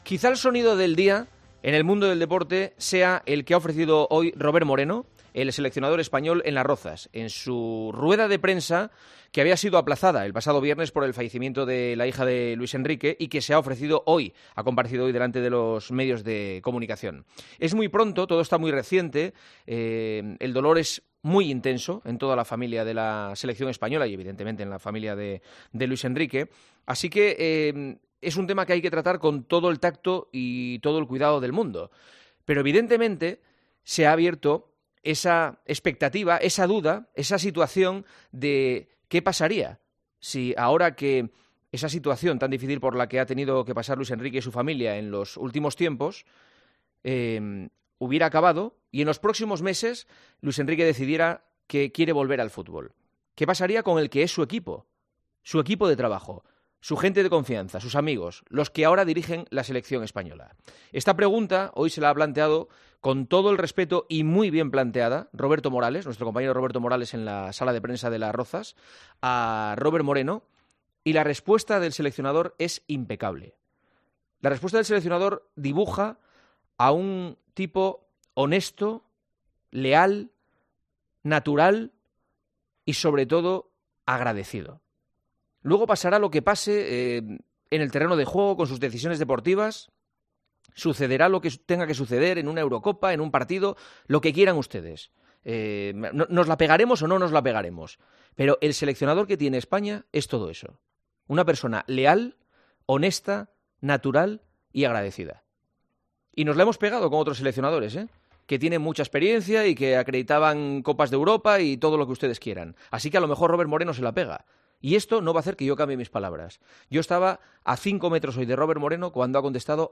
Juanma Castaño ha estado esta mañana en esa rueda de prensa, y el director de 'El Partidazo de COPE', tras escuchar al seleccionador nacional, ha querido dedicarle las siguientes palabras al comienzo del programa: